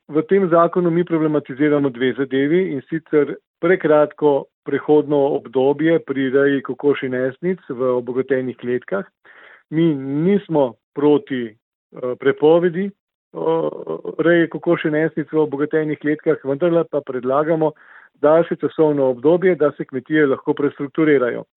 izjava Podgorsek 2 za splet.mp3